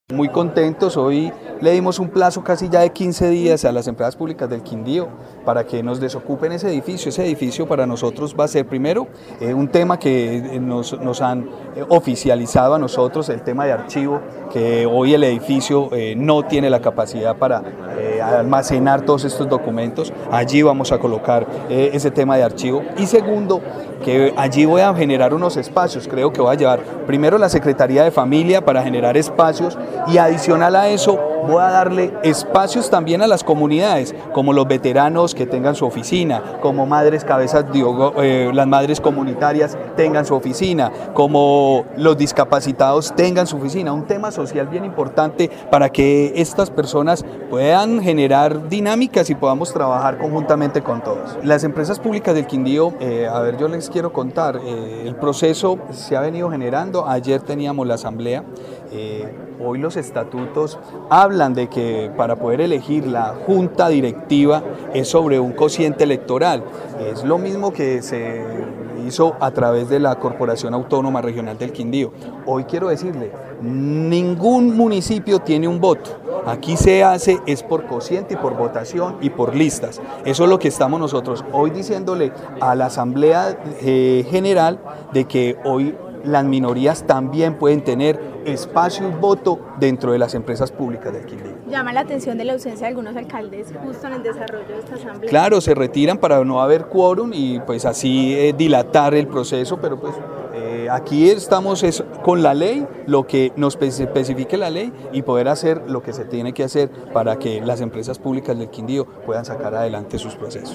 Gobernador del Quindío, Juan Miguel Galvis